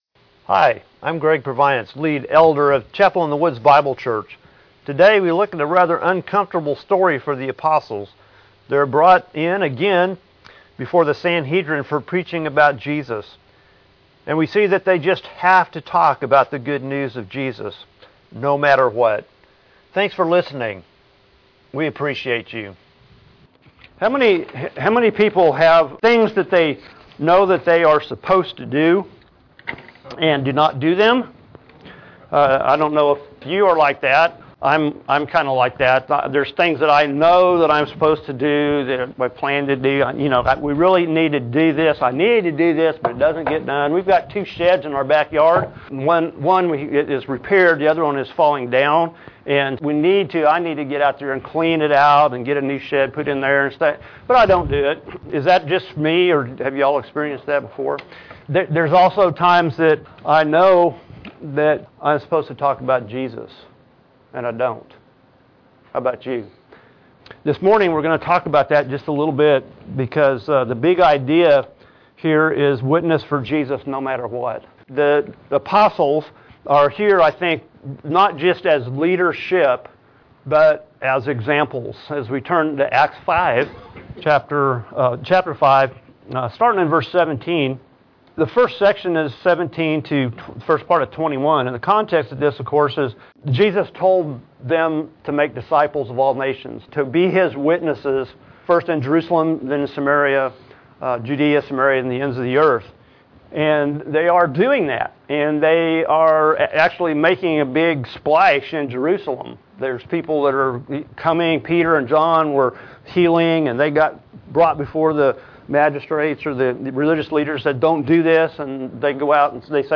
Feb 18, 2018 Acts 5:17-42 Witness no matter what MP3 SUBSCRIBE on iTunes(Podcast) Notes Discussion Sermons in this Series The Apostles told they can't do what Jesus told them to do.